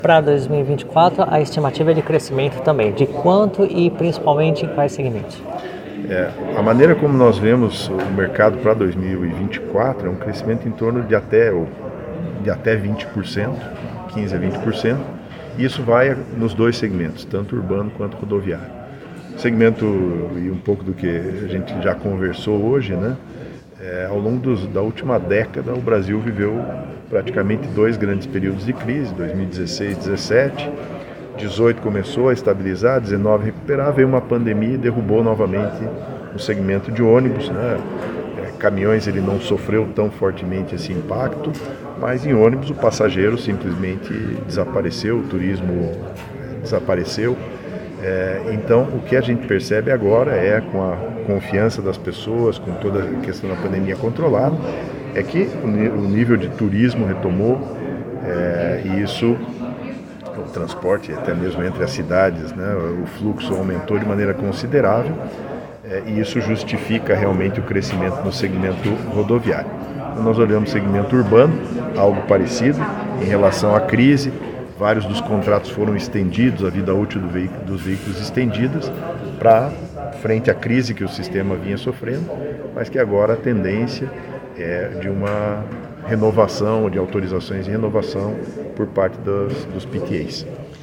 ENTREVISTA: Volvo estima crescer 20% no mercado de ônibus em 2024